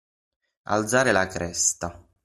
Pronounced as (IPA) /ˈkres.ta/